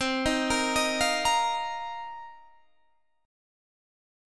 B#min11 Chord (page 2)
Listen to B#min11 strummed